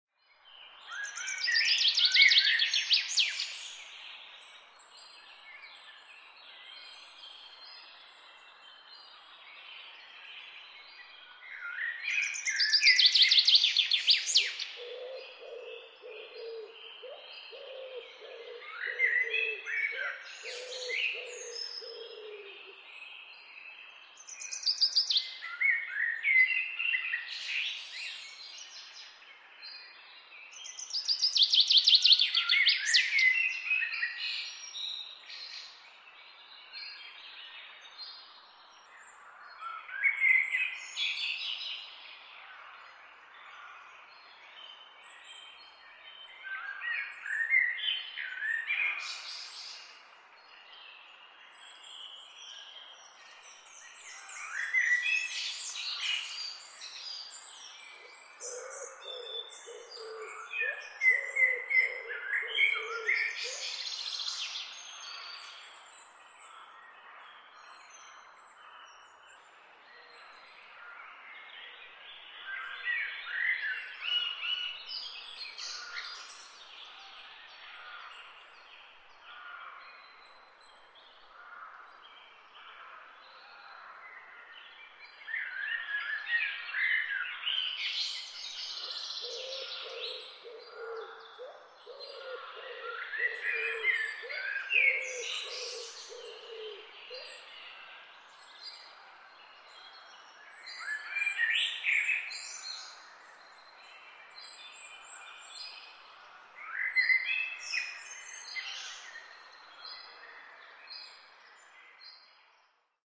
Birds-sound-effect.mp3